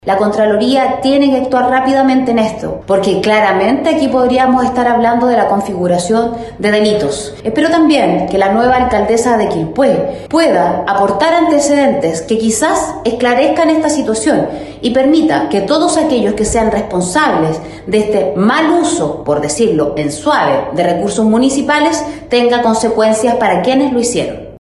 En tanto, la diputada por el 6º distrito, Camila Flores (RN), instó a la Contraloría a actuar con celeridad, considerando que, a su juicio, podríamos estar en presencia de delitos.